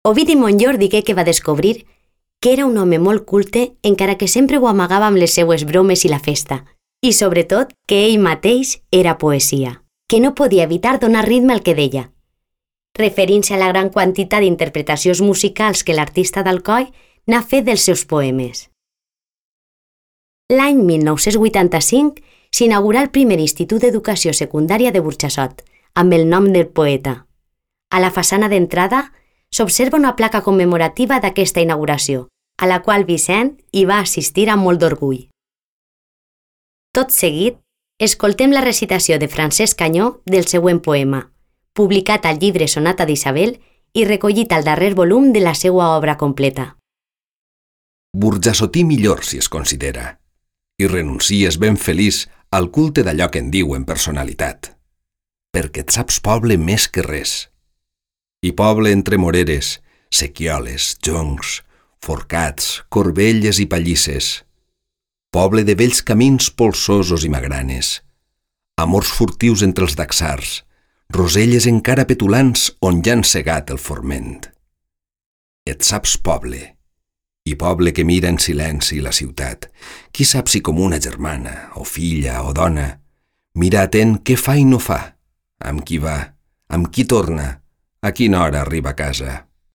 Explicació en àudio:
Tot seguit, escoltem la recitació de Francesc Anyó del següent poema, publicat al llibre Sonata d’Isabel i recollit al darrer volum de la seua obra completa: